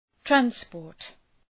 Προφορά
{træns’pɔ:rt}